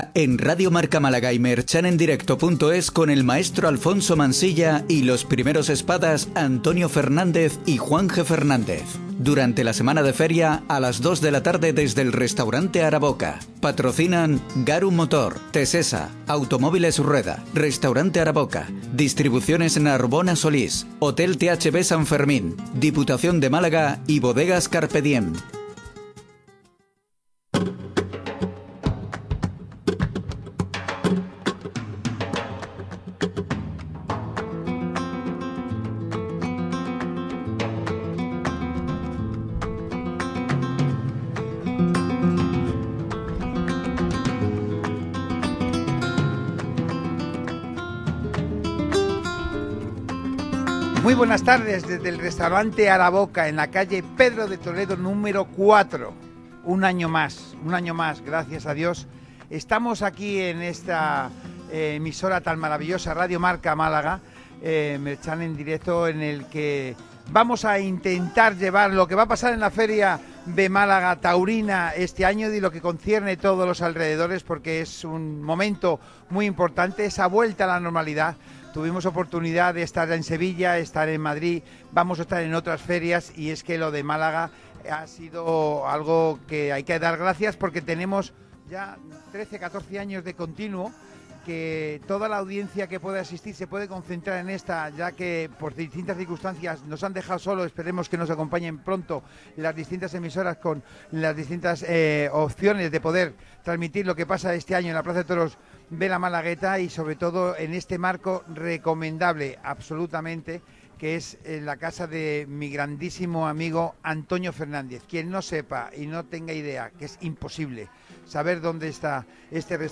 Restaurante Araboka, anfitrión de las tertulias taurinas de Radio Marca Málaga - Radio Marca Málaga